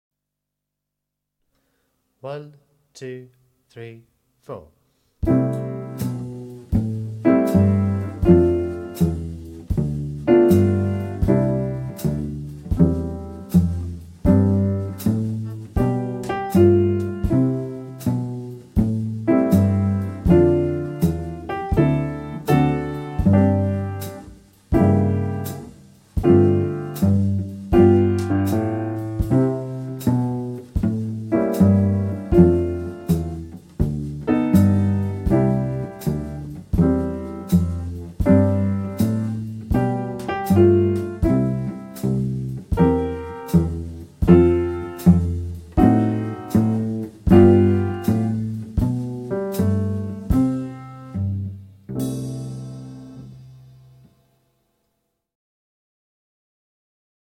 36 Walking bass (Cello)